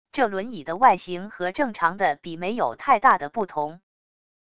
这轮椅的外形和正常的比没有太大的不同wav音频生成系统WAV Audio Player